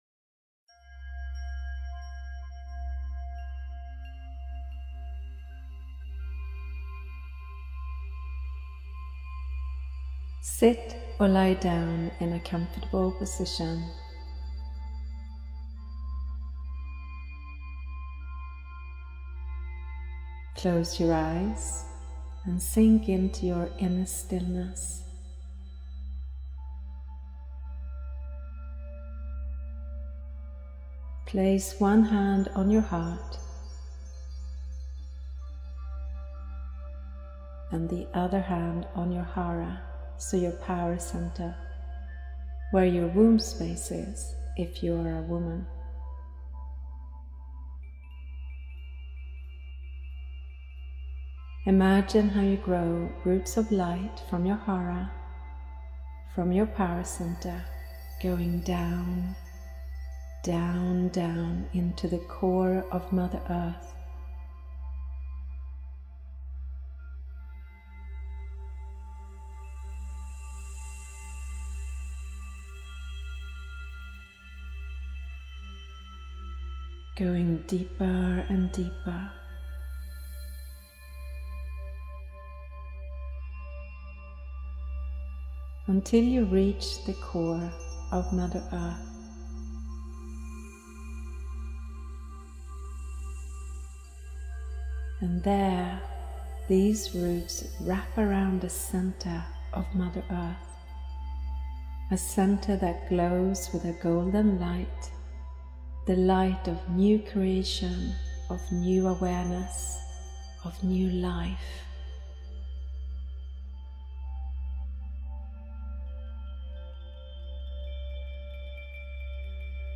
Thank you for signing up for your free meditation.